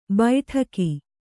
♪ baiṭhaki